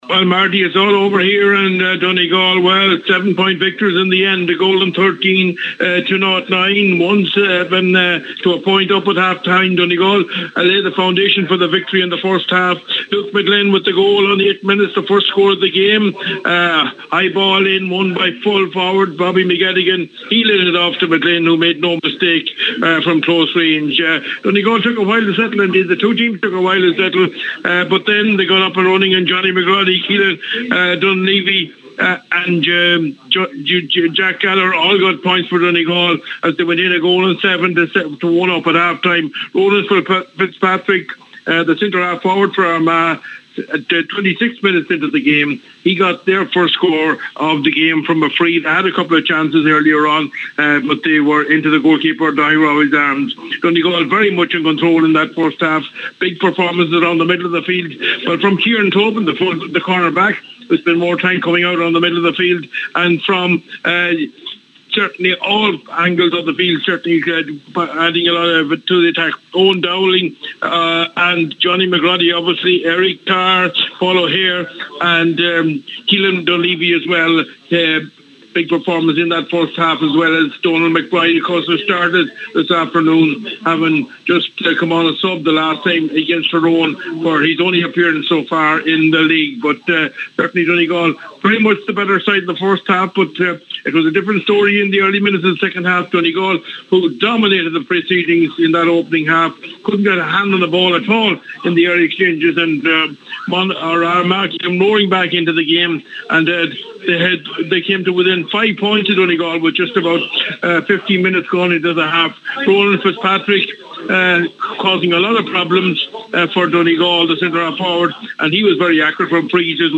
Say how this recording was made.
reports from MacCumhaill Park…